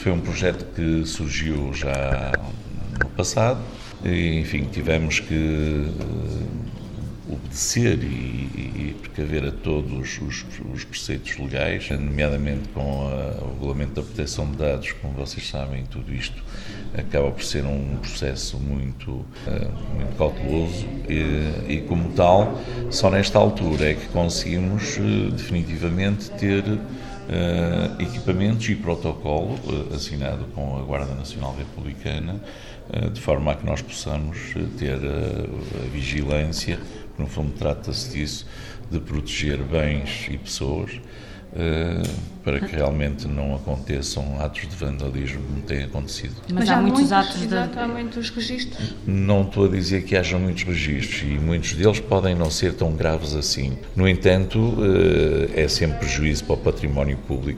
O protocolo foi assinado, esta quarta-feira, entre o município e o Comando Territorial de Bragança da GNR e visa reforçar o compromisso conjunto entre as duas entidades, para uma maior segurança e proteção das populações, como destacou Benjamim Rodrigues, presidente da câmara municipal de Macedo de Cavaleiros: